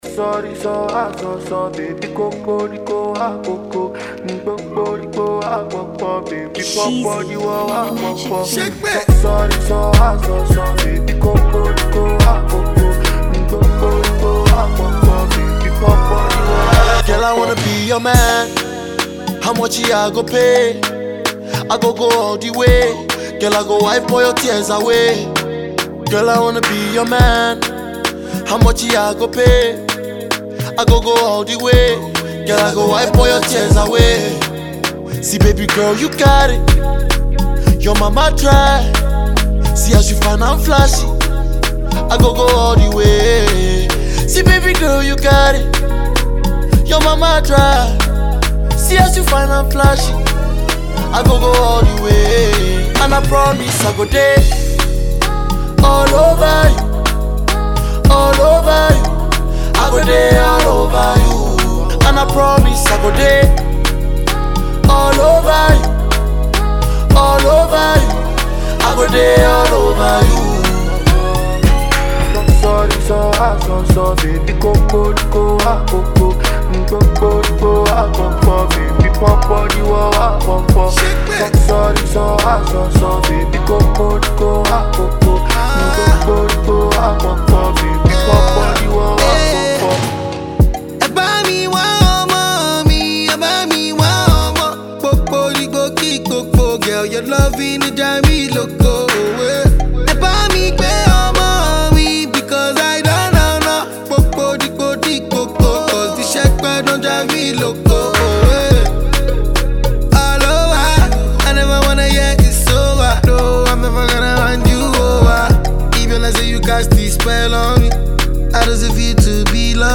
catchy tune
Ghanaian rapper